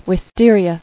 [wis·ter·i·a]